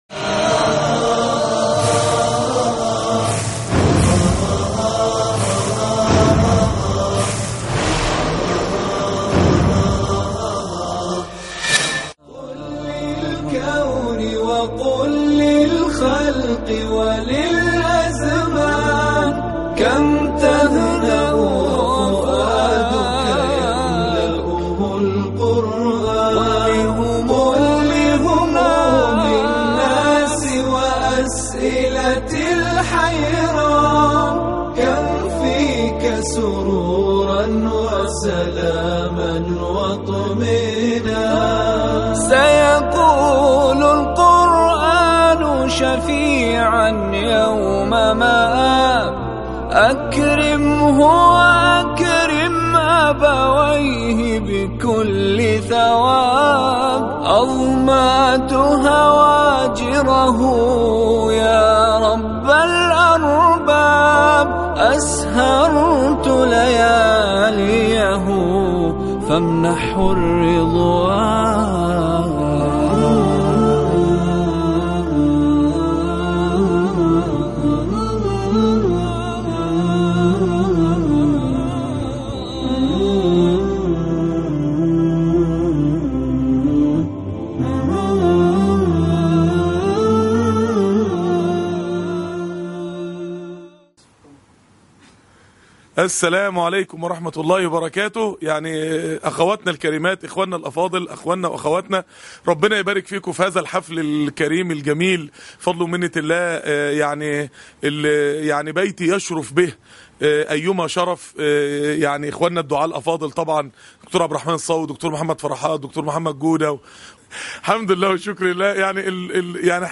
حفل تكريم الناجحين في الفصل الدراسي الأول للمعهد القرآني الدعوي